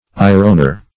Ironer \I"ron*er\, n. One who, or that which, irons.